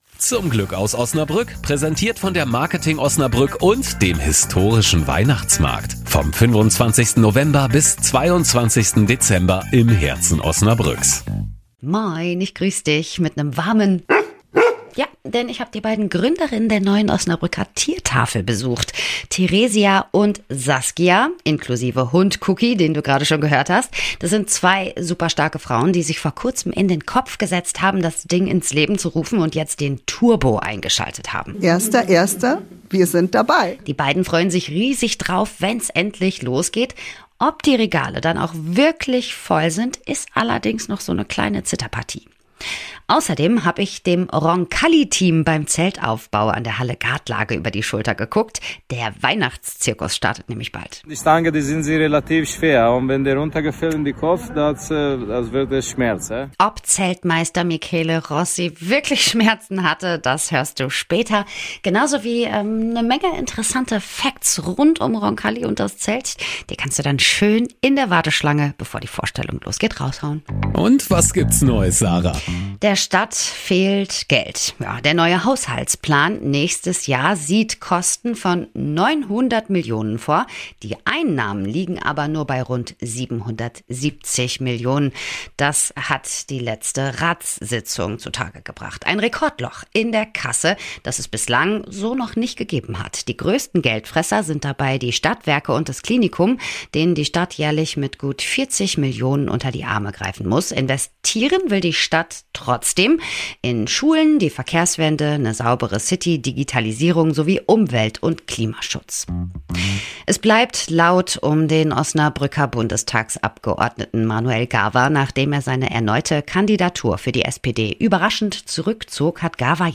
Roncalli steht mit seinem Weihnachtscircus an der Halle Gartlage in den Startlöchern. Ich hab beim Zeltaufbau schlaue Fragen gestellt